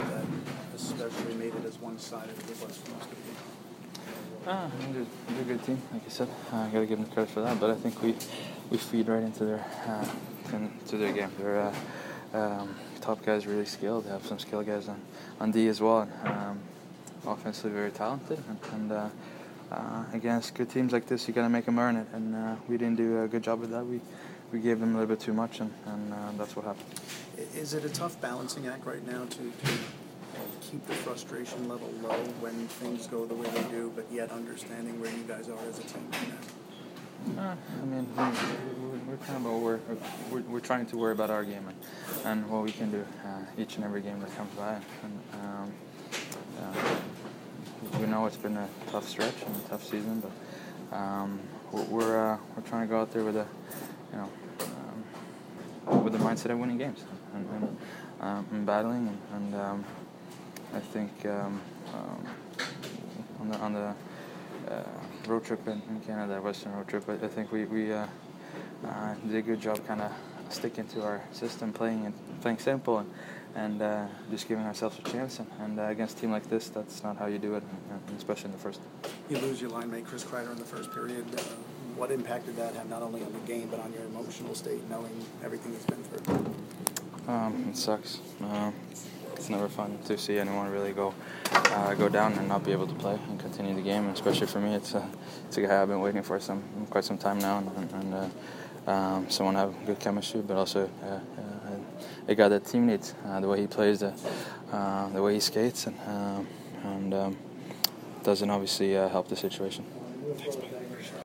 Mika Zibanejad post-game 3/8